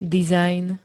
dizajn [-d-] -nu pl. N -ny I -nami/-nmi m.
Zvukové nahrávky niektorých slov